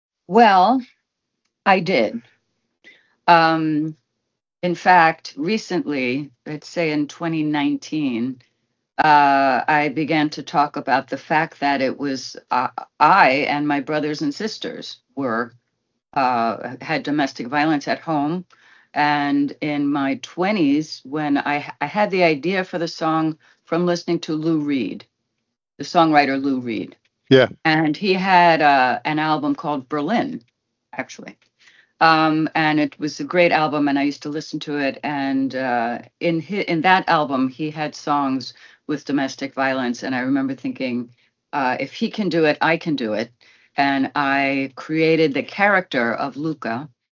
Im SWR1 Interview haben wir mit ihr über ihren großen Hit "Luka" gesprochen und sie gefragt, wie sie die 80er und 90er erlebt hat.